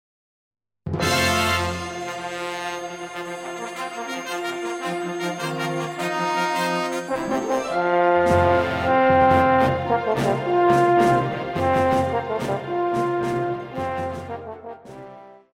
Pop
French Horn
Band
Instrumental
World Music,Fusion
Only backing